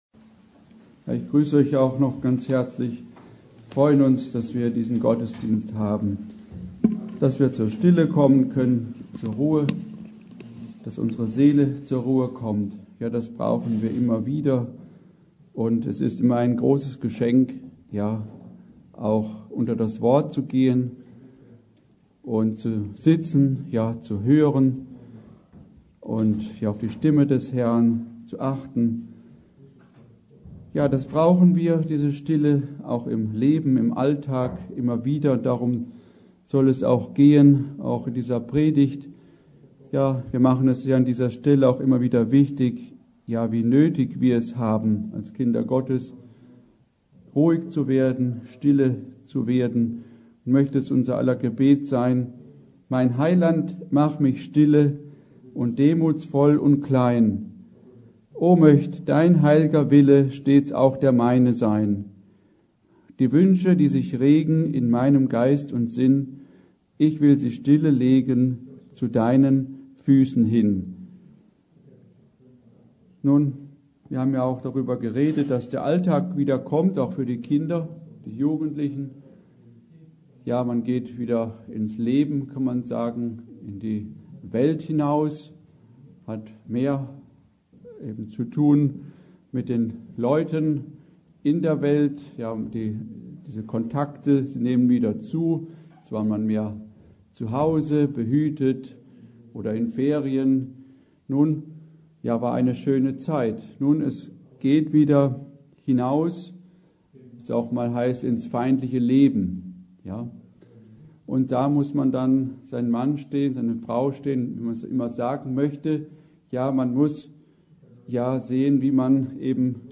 Predigt: Stille vor Gott